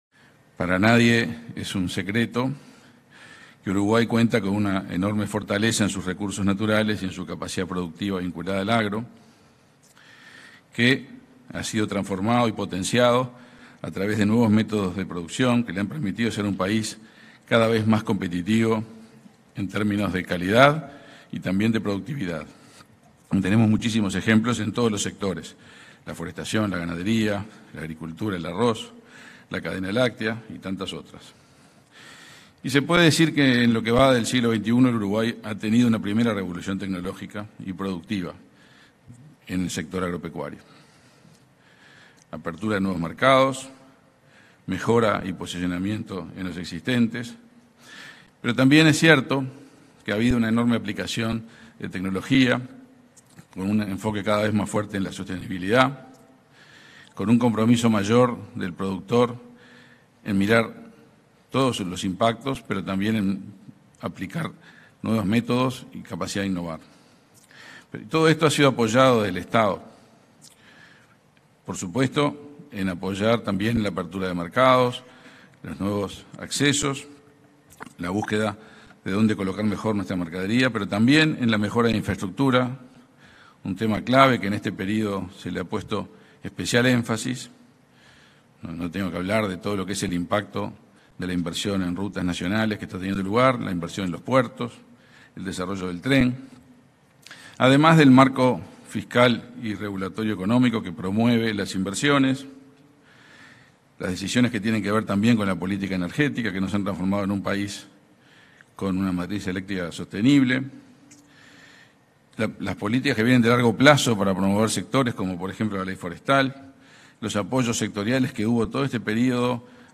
Palabras del ministro de Relaciones Exteriores, Omar Paganini | Presidencia Uruguay
En el marco de la feria agroexportadora denominada Agro en Punta Expo & Business, este 31 de enero, se expresó el ministro de Relaciones Exteriores,